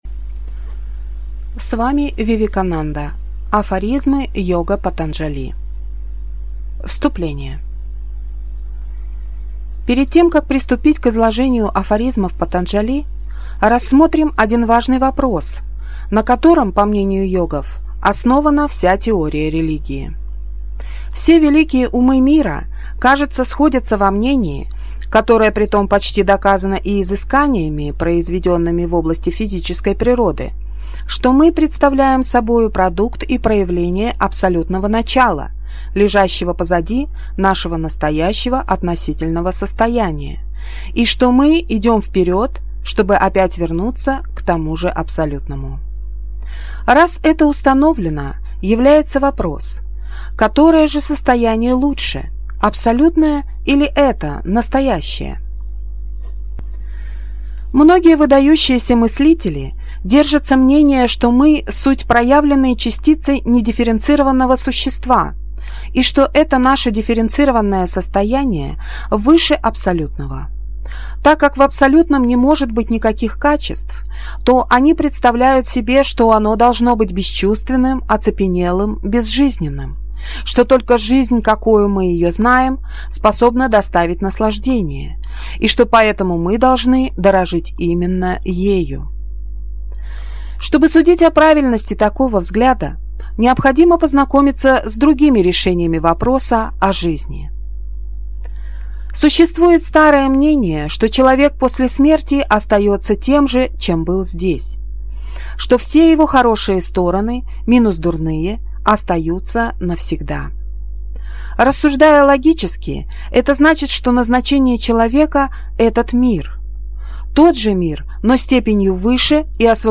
Аудиокнига Афоризмы йога Патанджали | Библиотека аудиокниг